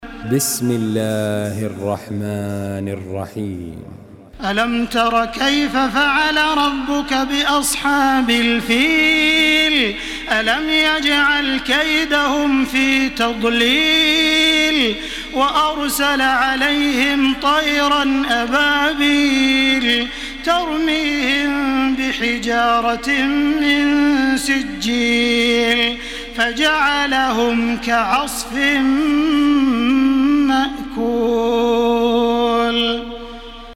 سورة الفيل MP3 بصوت تراويح الحرم المكي 1433 برواية حفص عن عاصم، استمع وحمّل التلاوة كاملة بصيغة MP3 عبر روابط مباشرة وسريعة على الجوال، مع إمكانية التحميل بجودات متعددة.
تحميل سورة الفيل بصوت تراويح الحرم المكي 1433